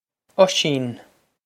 Oisín Oh-sheen
This is an approximate phonetic pronunciation of the phrase.